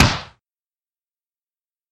звук удара кулака в стену